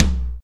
RX LO TOM.wav